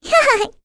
May-Vox_Happy1.wav